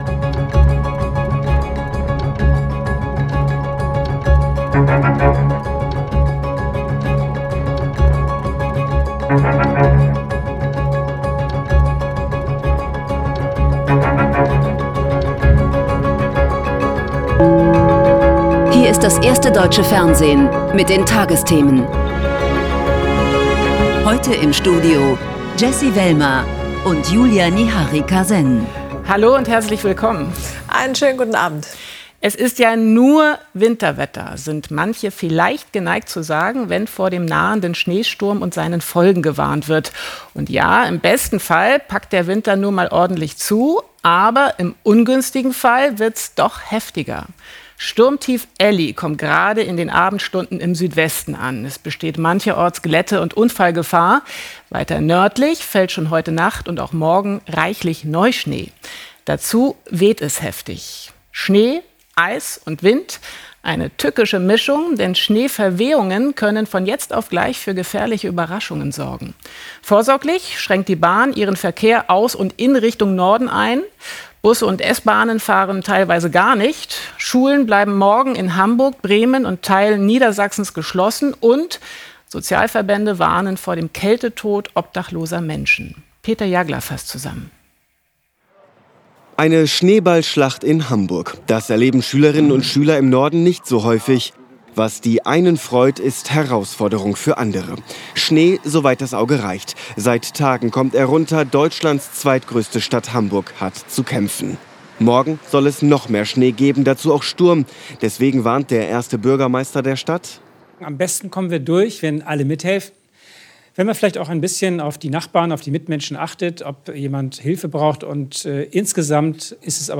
… continue reading 2655 episodi # Tägliche Nachrichten # Nachrichten # Tagesschau